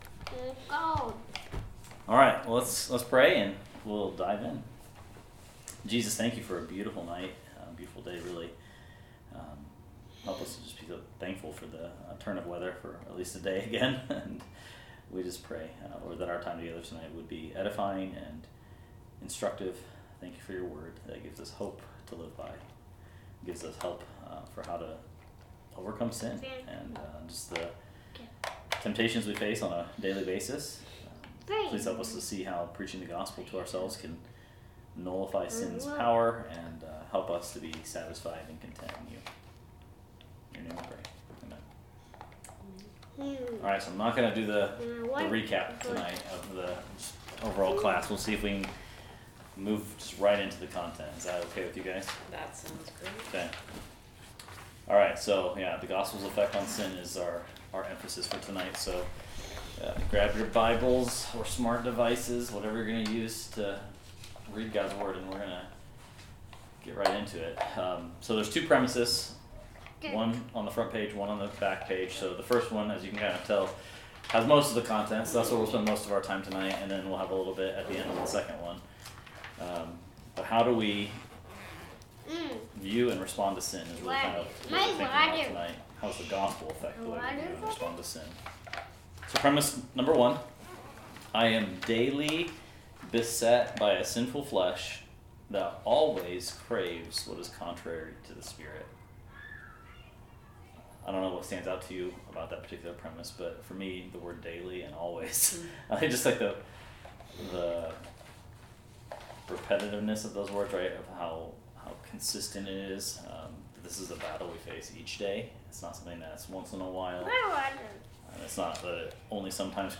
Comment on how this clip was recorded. Meaning the audio is recorded from a mic that picks up the whole room and has only received a minimum amount of editing afterwards.